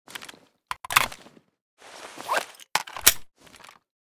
svd_reload.ogg